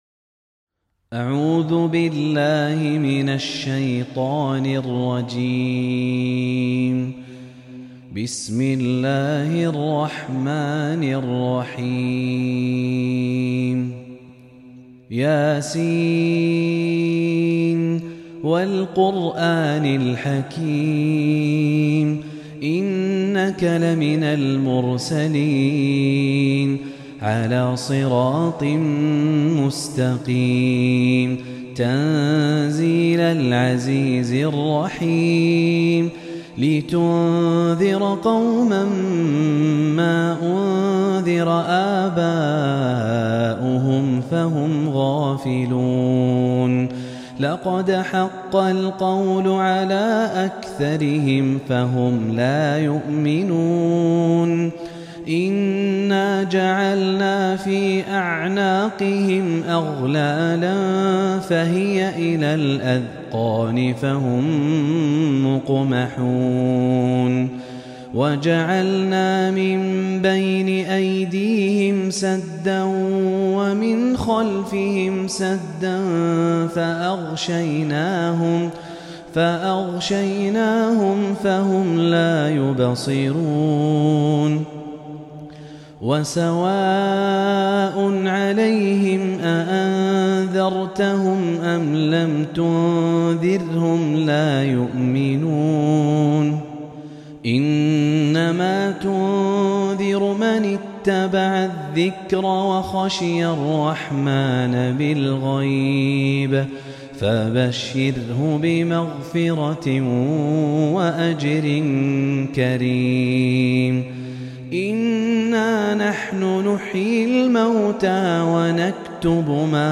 تلاوة مميزة لسورة يس كاملة